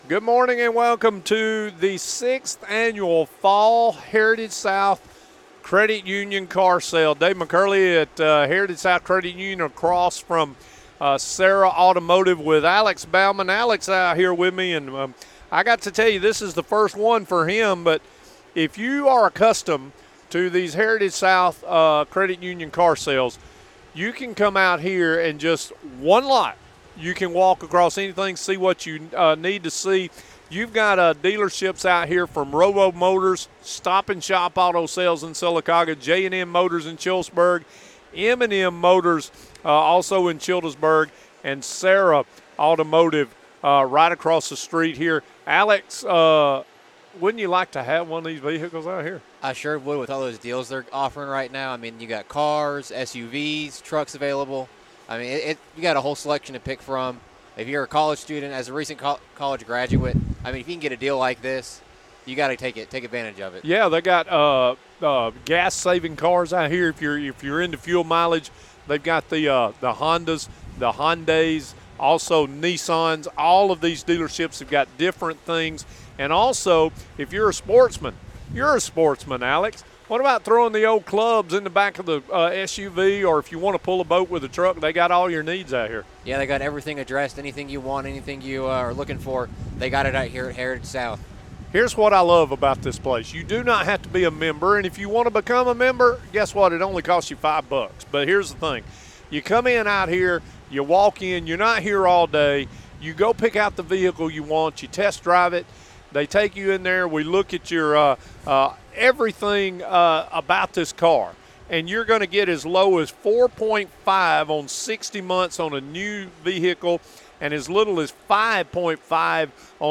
Live from Heritage South Credit Union's 6th Annual Fall Car Sale